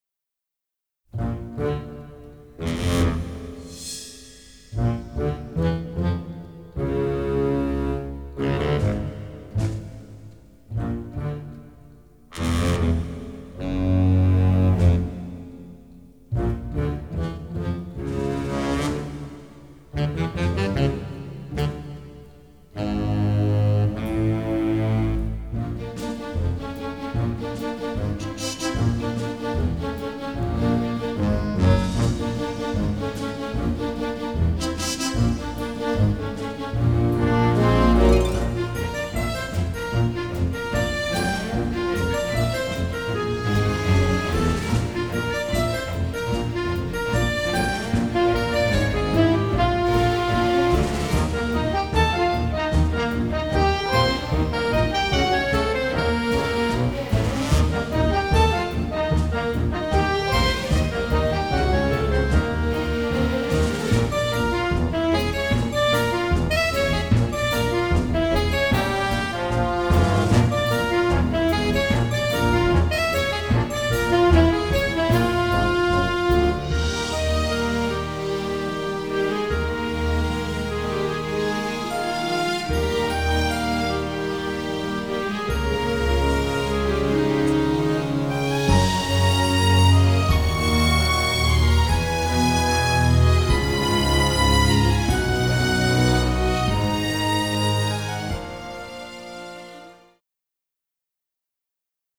generous string section plus brass ensemble